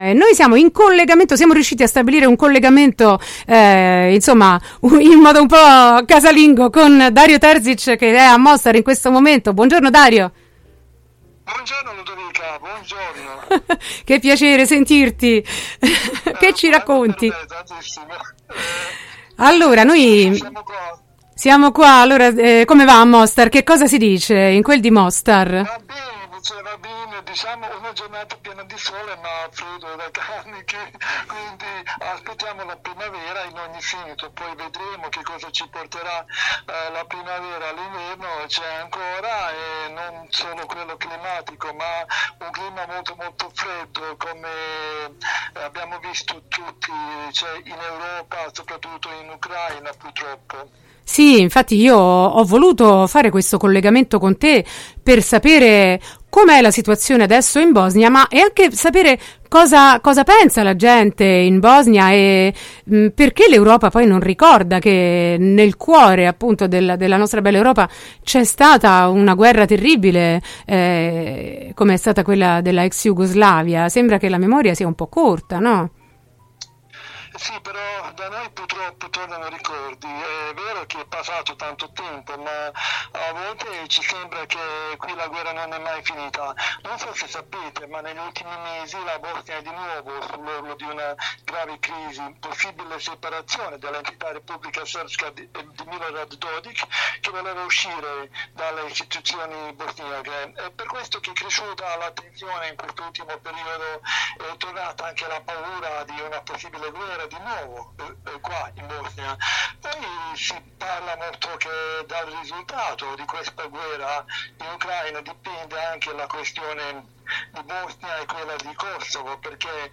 Bosnia, il conflitto dimenticato: intervista